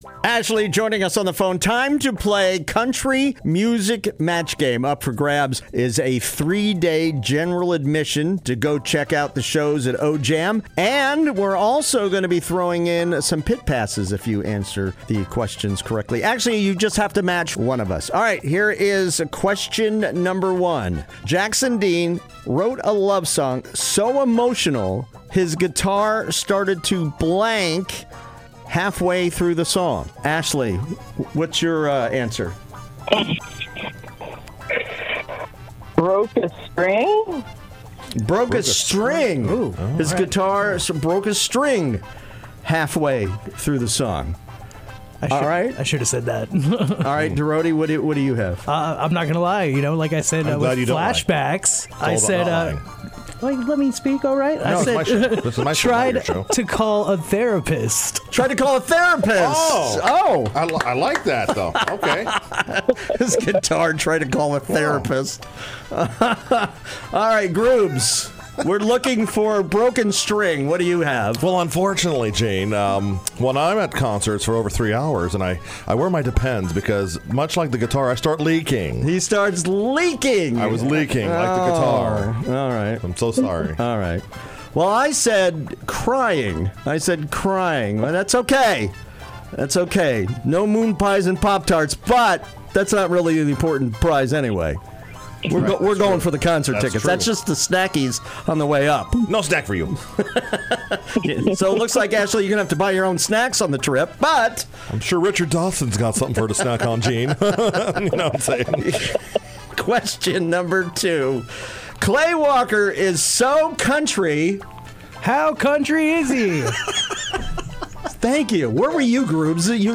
Just call in and have fun and YOU’RE A WINNER!